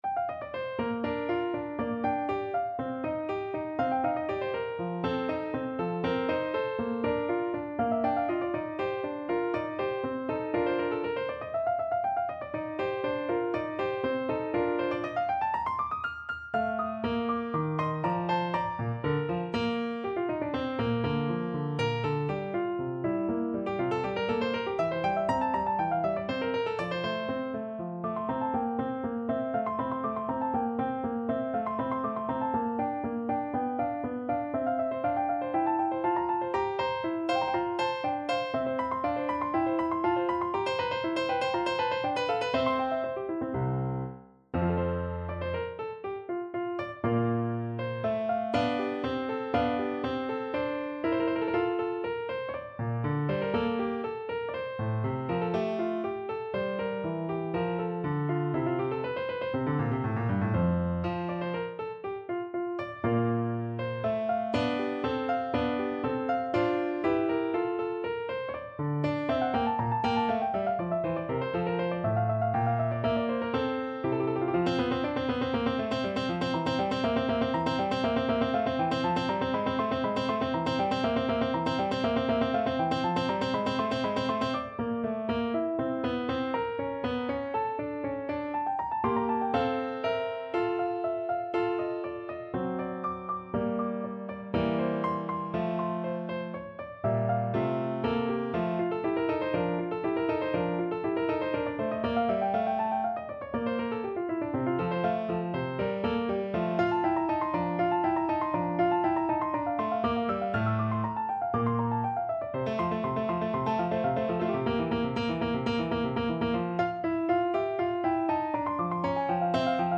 No parts available for this pieces as it is for solo piano.
Bb major (Sounding Pitch) (View more Bb major Music for Piano )
4/4 (View more 4/4 Music)
Allegro (View more music marked Allegro)
Piano  (View more Intermediate Piano Music)
Classical (View more Classical Piano Music)